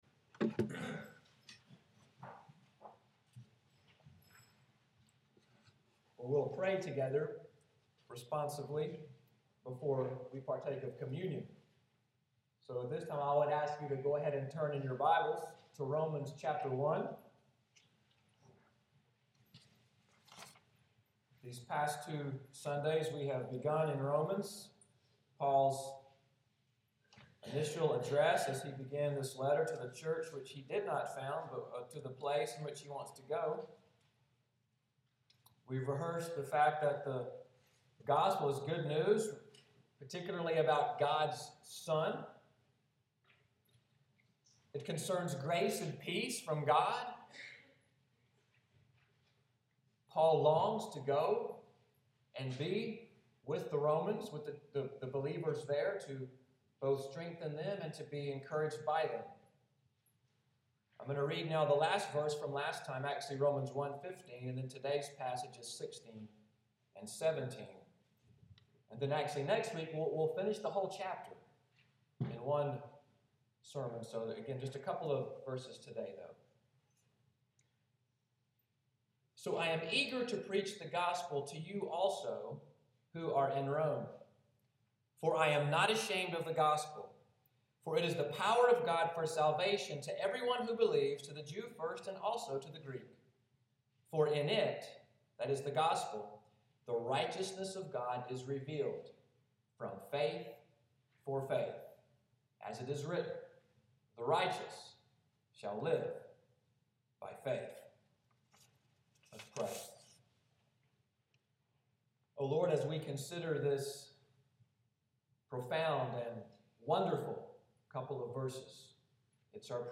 Sunday’s sermon, “The Gates of Paradise,” February 1, 2015